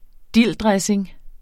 Udtale [ ˈdilˀ- ]